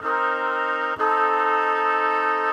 Index of /musicradar/gangster-sting-samples/95bpm Loops
GS_MuteHorn_95-C2.wav